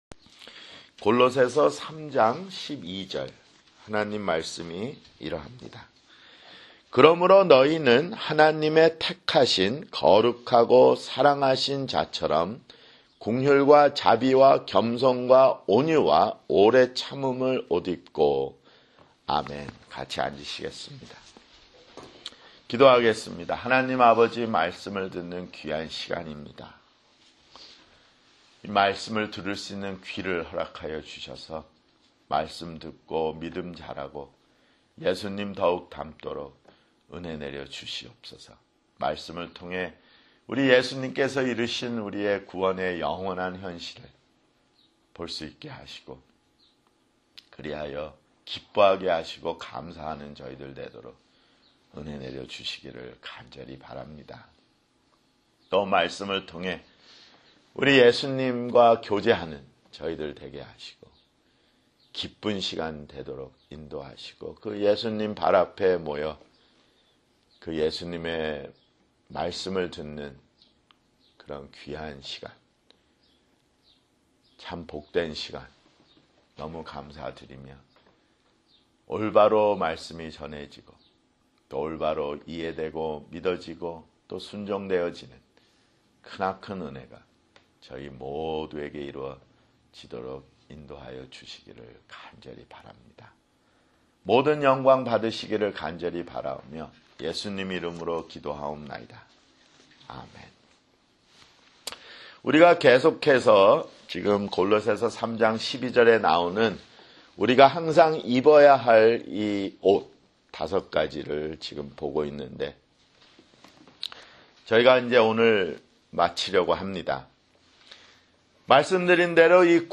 [주일설교] 골로새서 (66)